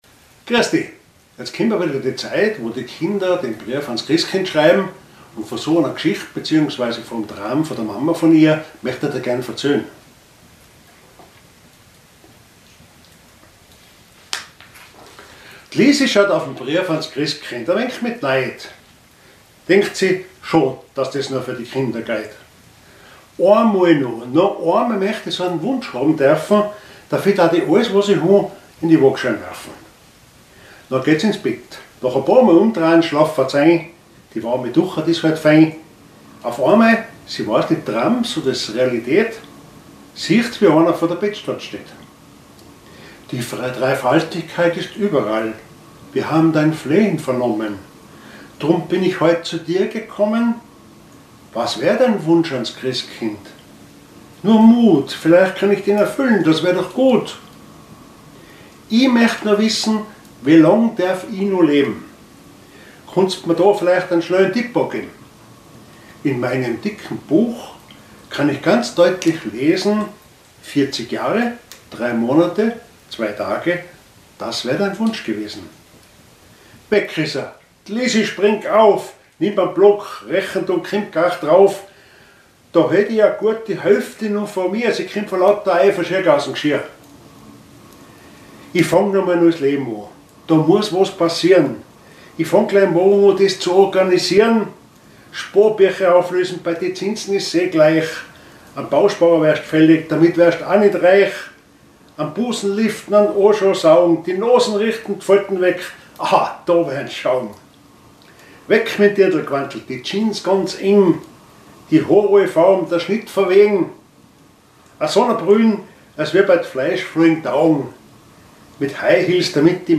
Mundart